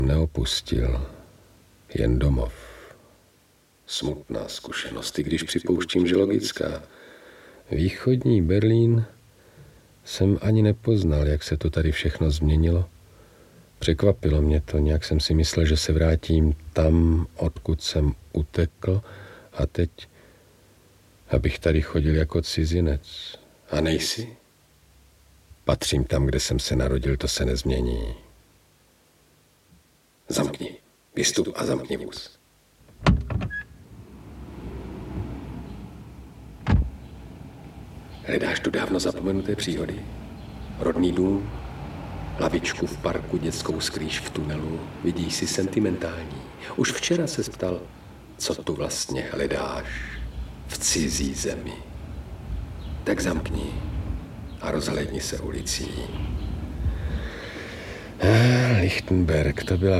Audiobook
Audiobooks » Short Stories
Read: Karel Höger